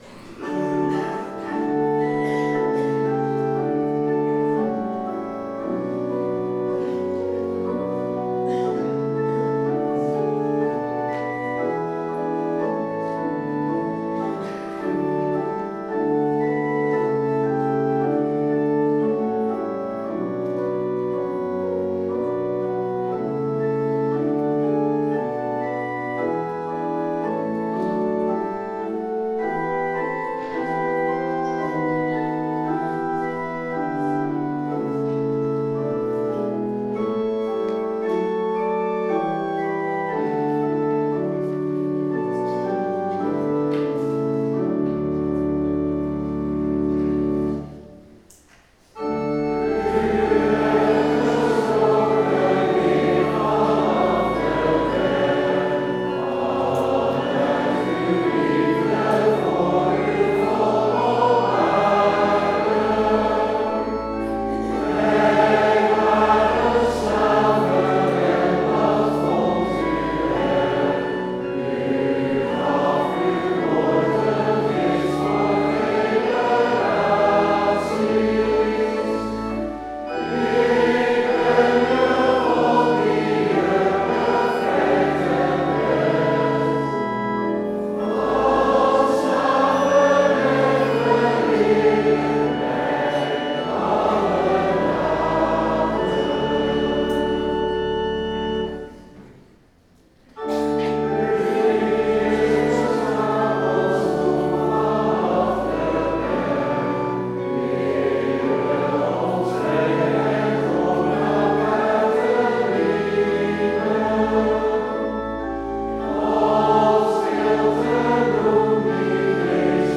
De eenvoudige melodie van dit lied is simpel van opzet. Regel 1 / 2 is gelijk aan regel 3 / 4. Regel 5 is hetzelfde als regel 1, maar één toon hoger. Regel 6 herhaalt dat motief nog een toon hoger en sluit daarna af op de grondtoon.